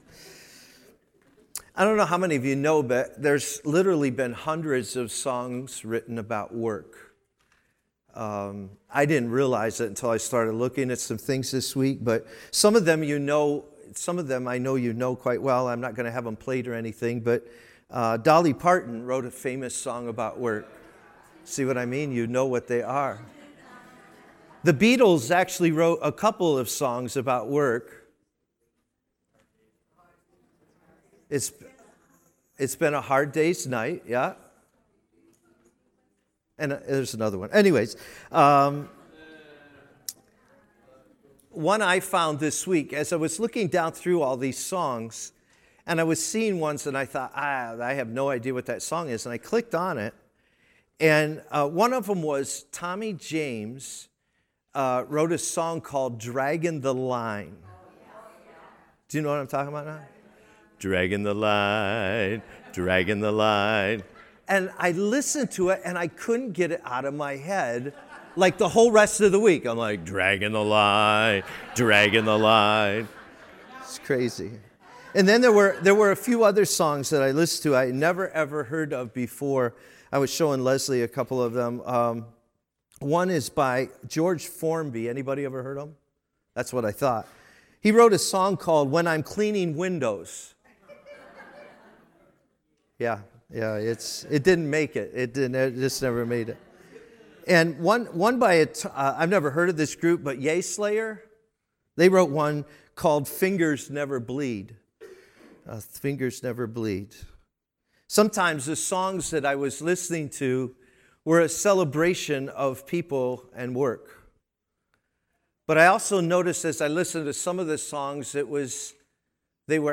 Stand Alone Message